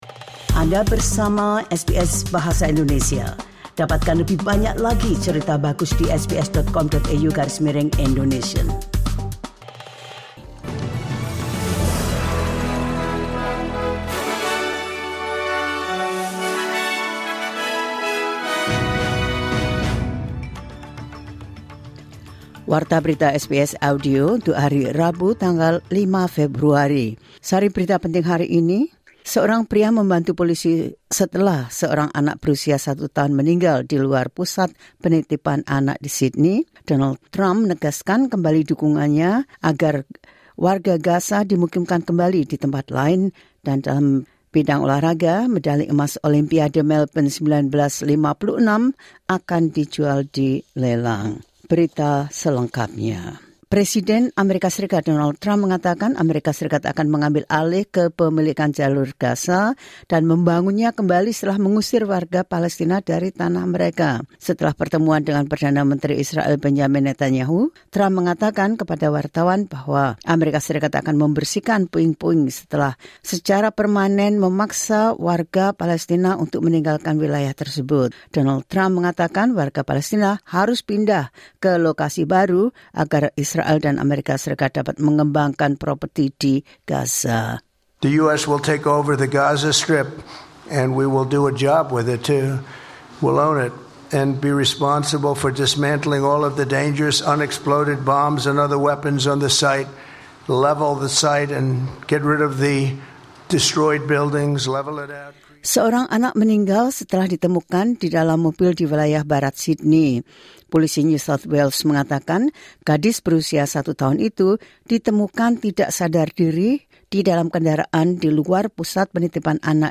Latest News SBS Audio Indonesian Program – 05 February 2025